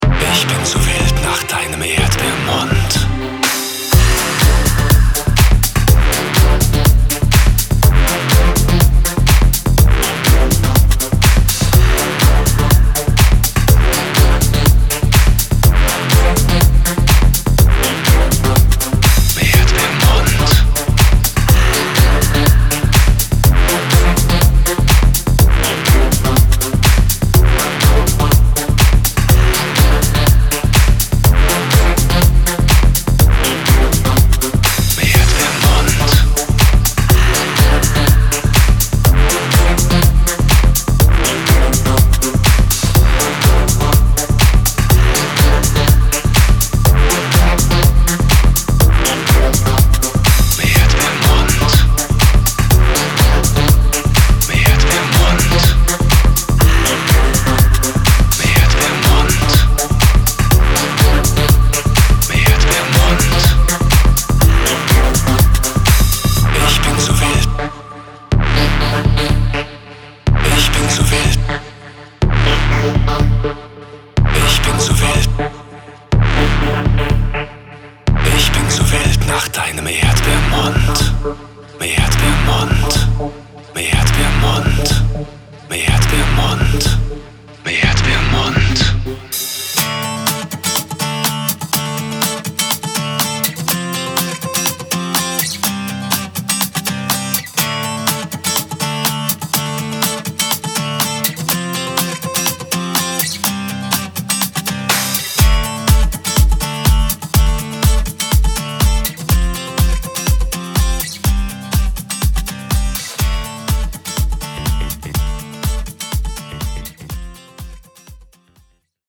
Vocals
Gitarre